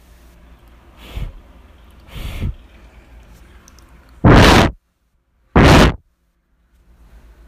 Para não restar dúvidas, fiz um teste bem simples: abri o app Gravador e soprei em cima do microfone frontal e, em seguida, no microfone inferior/principal.
Sopro.m4a